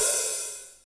Index of /90_sSampleCDs/Sound & Vision - Gigapack I CD 1 (Roland)/KIT_REAL m 1-16/KIT_Real-Kit m 3